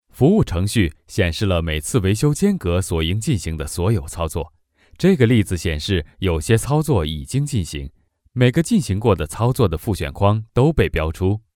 I have my own home studio which can provide qualified recording and fast turn arround.
Sprechprobe: eLearning (Muttersprache):